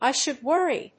アクセントI should wórry! 《米口語》